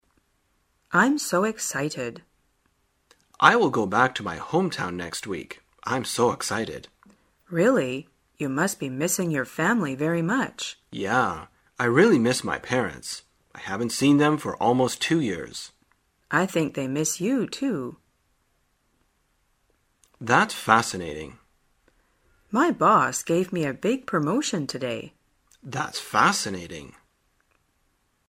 旅游口语情景对话 第331天:如何表示激动